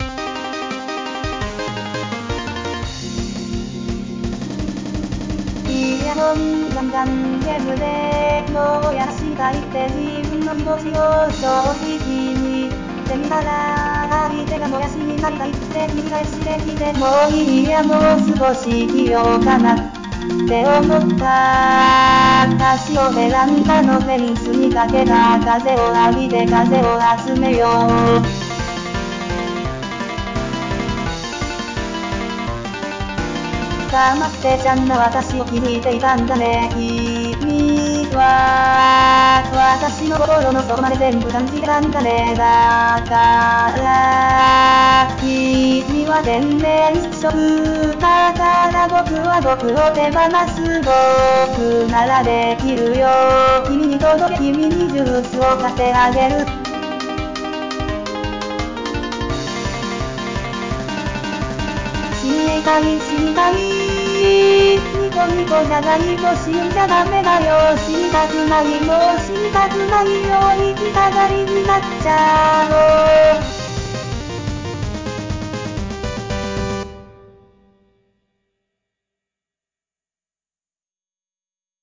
日本語歌詞からAI作曲し、伴奏つき合成音声で最長10分歌います。